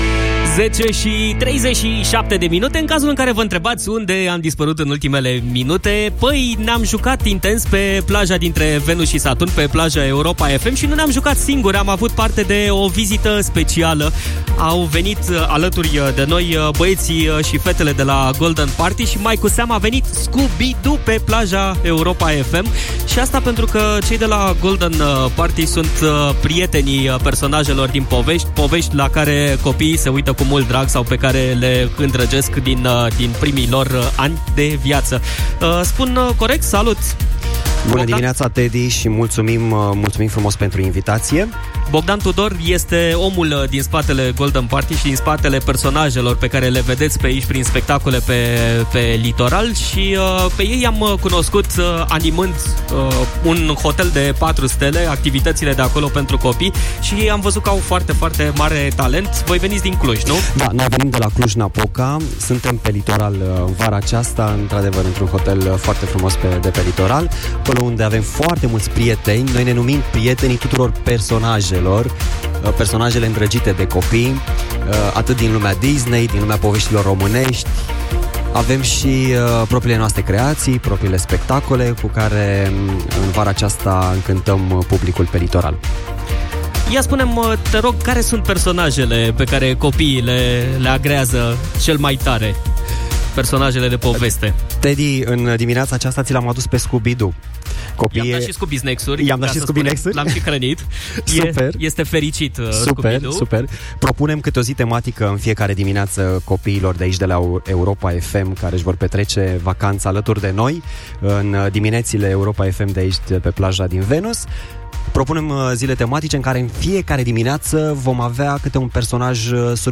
Scooby-Doo a mirosit bine unde este cea mai prietenoasă plajă de pe litoral și a ajuns, sâmbătă, pe Plaja Europa FM, direct într-O Dimineață de milioane.